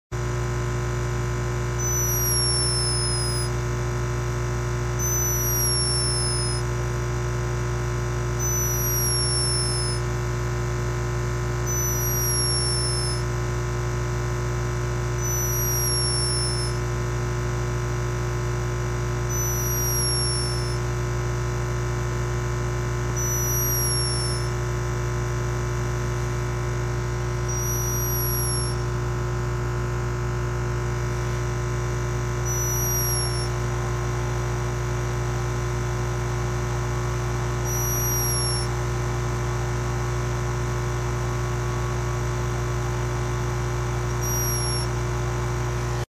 Esse helianjo de garganta ametista (Heliangelus amethysticollis) está sound effects free download
Esse helianjo-de-garganta-ametista (Heliangelus amethysticollis) está "roncando", ou pelo menos fazendo algo parecido. Beija-flores passam por um processo chamado torpor, um estado de atividade fisiológica reduzida, marcado principalmente por redução da temperatura corporal e taxa metabólica.